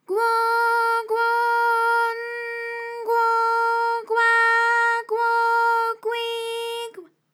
ALYS-DB-001-JPN - First Japanese UTAU vocal library of ALYS.
gwo_gwo_n_gwo_gwa_gwo_gwi_gw.wav